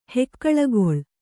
♪ hekkaḷagoḷ